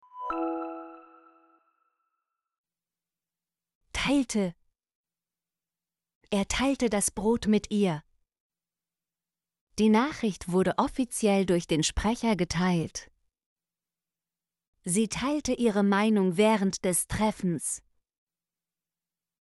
teilte - Example Sentences & Pronunciation, German Frequency List